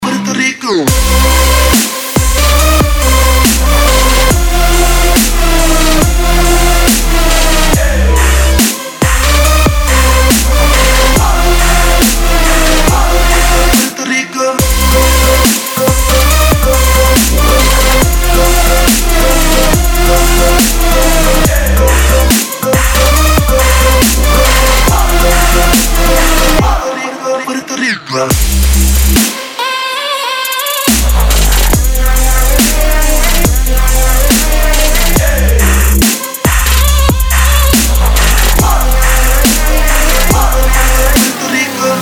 • Качество: 192, Stereo
Dubstep